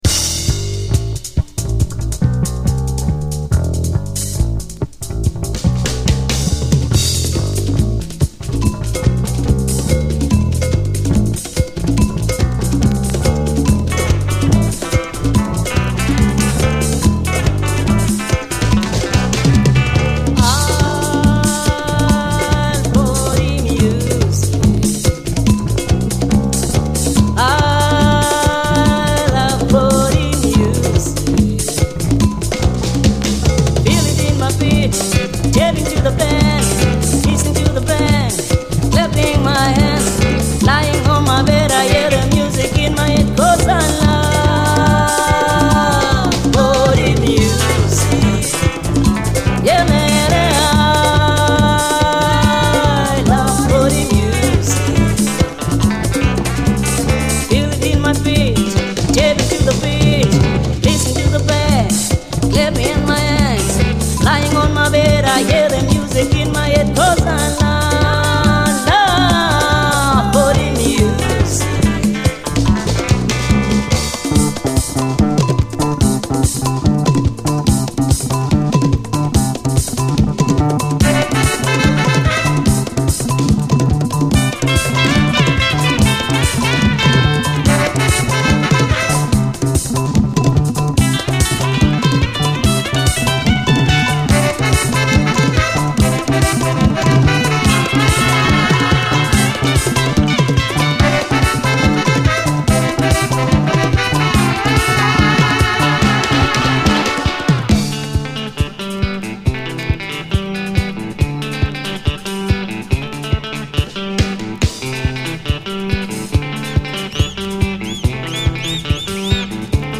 DISCO, AFRO, 80's～ ROCK, WORLD, ROCK